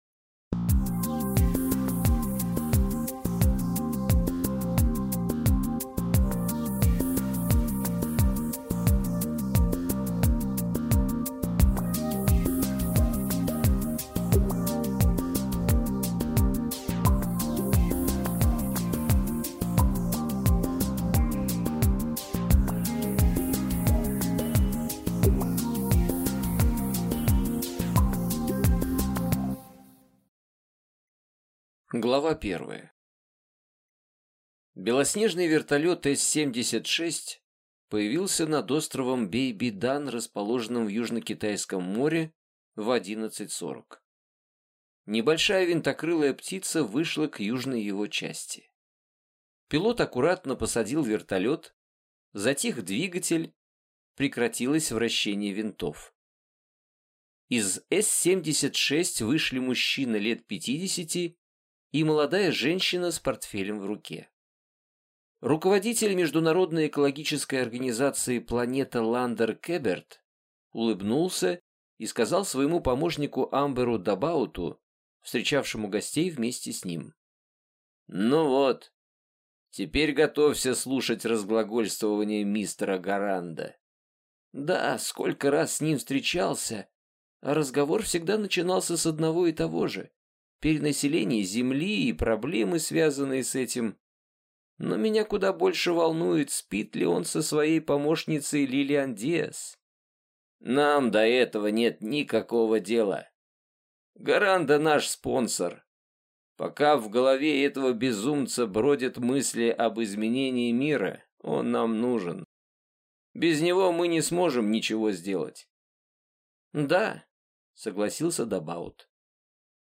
Аудиокнига Нулевой пациент | Библиотека аудиокниг